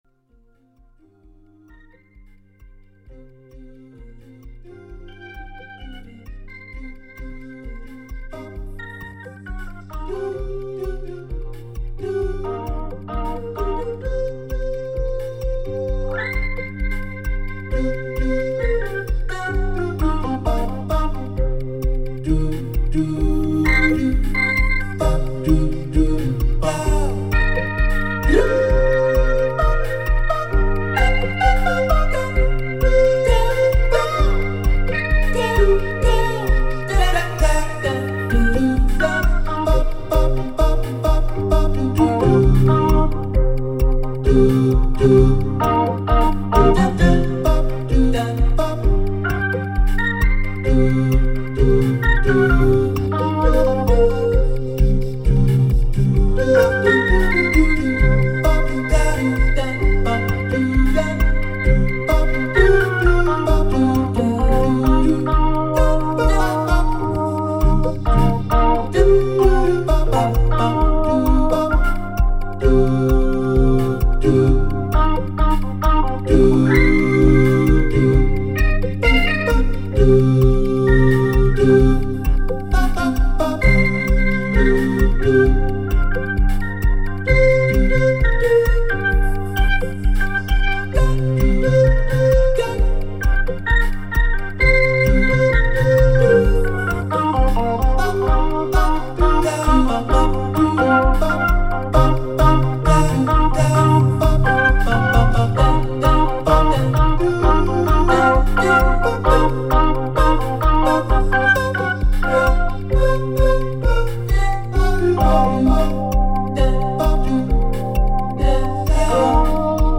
Tempo: 66 bpm / Date: 03.03.2015